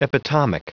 Prononciation du mot epitomic en anglais (fichier audio)
Prononciation du mot : epitomic